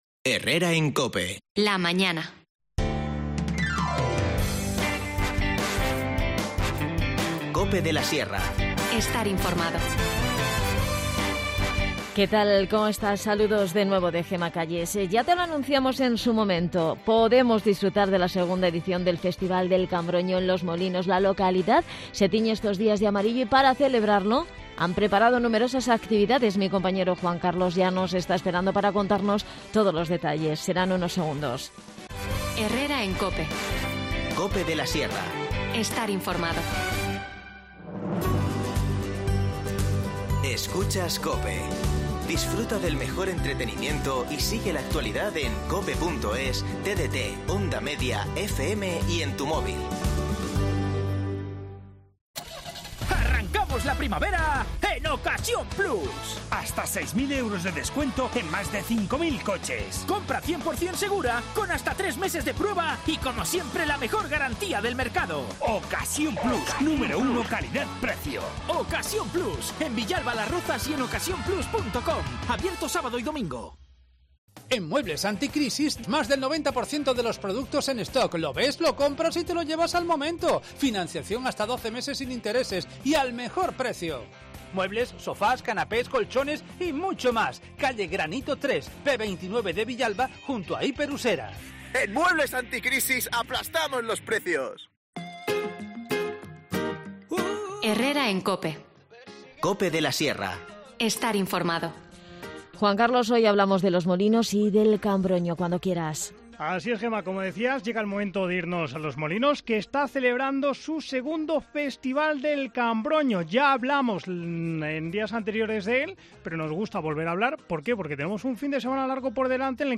Charlamos con Antonio Coello, alcalde de Los Molinos.